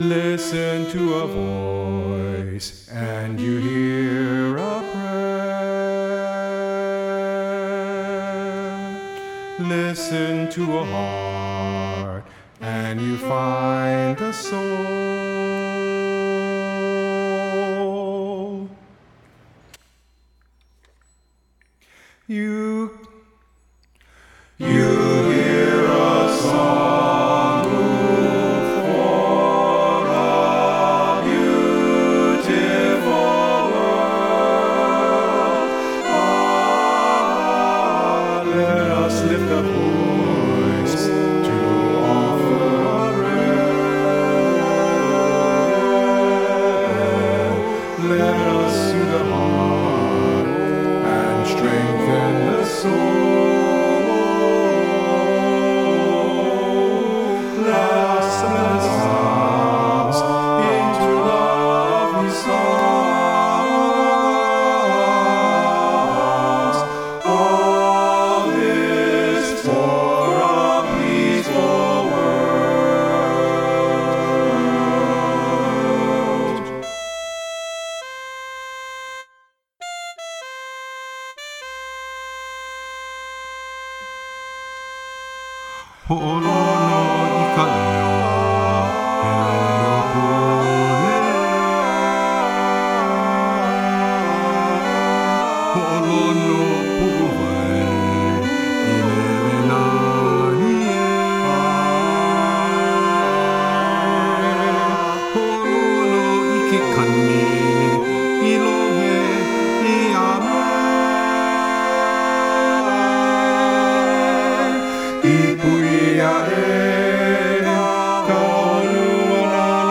1st pass: Vocal Ensemble ONLY, English
2nd pass: Choir, in parts, English   Bridge (4mm)